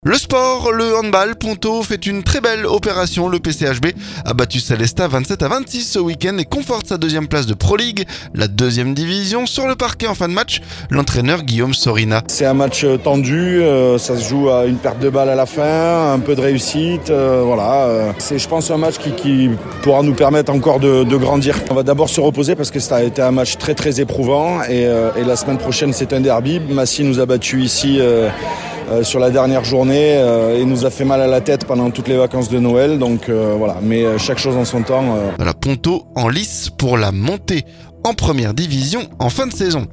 Le PCHB a battu Sélestat 27 à 26 ce week-end, et conforte sa deuxième place de Proligue, la deuxième division. Sur le parquet en fin de match